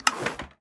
Minecraft Version Minecraft Version latest Latest Release | Latest Snapshot latest / assets / minecraft / sounds / ui / loom / select_pattern4.ogg Compare With Compare With Latest Release | Latest Snapshot